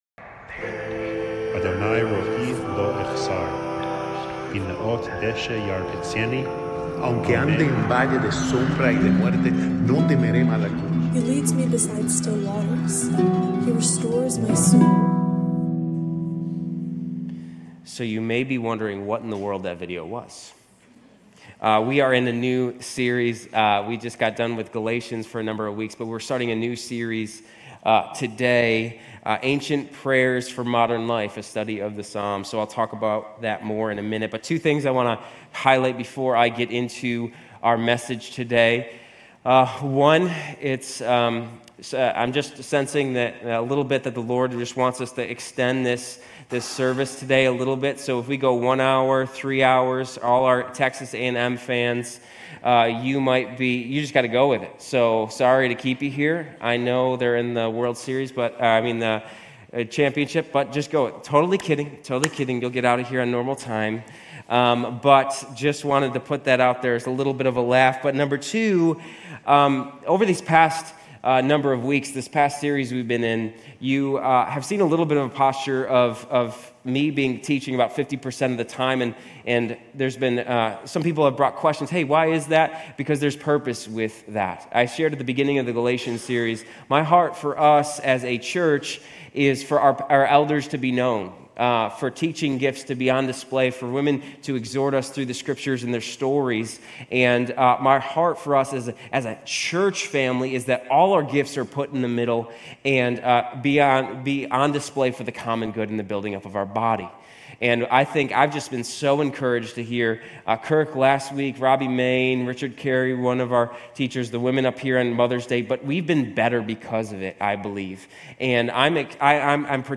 Grace Community Church University Blvd Campus Sermons Psalm 29 - Praise Jun 23 2024 | 00:33:27 1x 00:00 / 00:33:27 Subscribe Share RSS Feed Share Link Embed